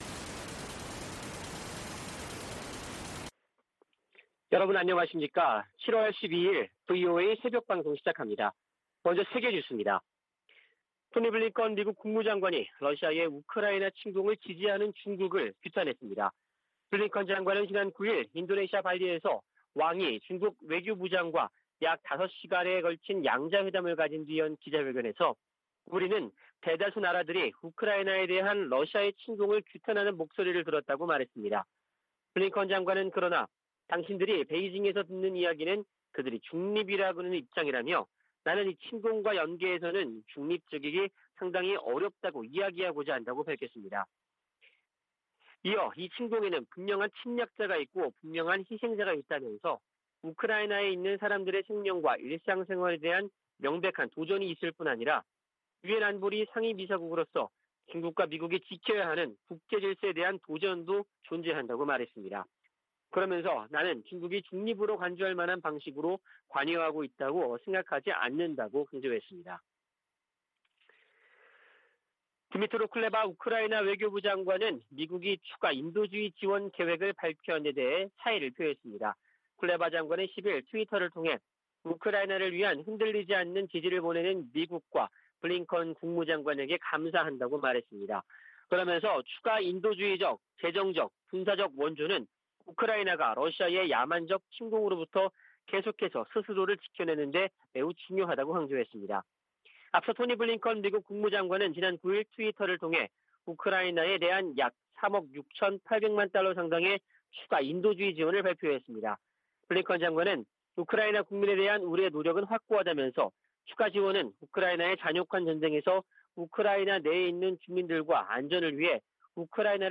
VOA 한국어 '출발 뉴스 쇼', 2022년 7월 12일 방송입니다. 북한이 한 달 만에 또 다시 서해로 방사포 2발을 발사했습니다. 미-한-일은 주요 20개국(G20) 외교장관 회의를 계기로 3자 회담을 갖고 안보협력 확대 방안을 협의했습니다. 미국과 한국이 다음달 22일부터 9월 1일까지 미-한 연합지휘소훈련(CCPT)을 진행하기로 했습니다.